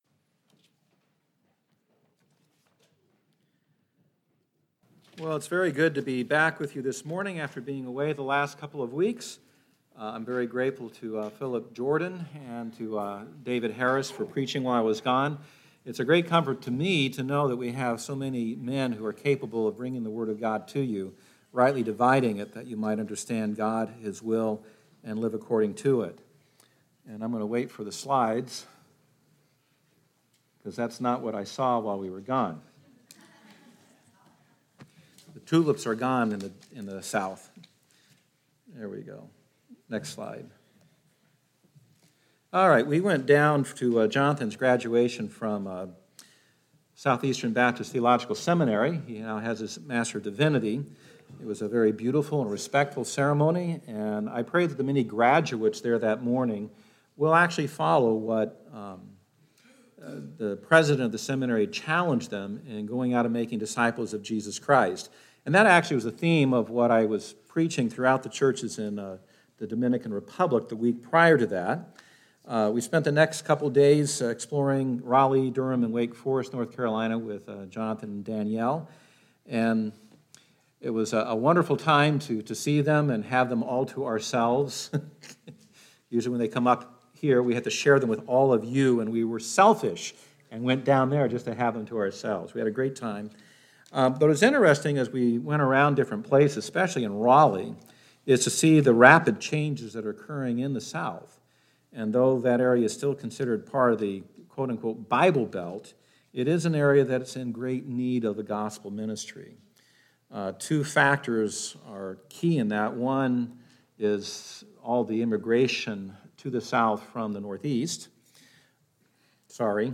Remaining Faithful: 25th Anniversary Sermon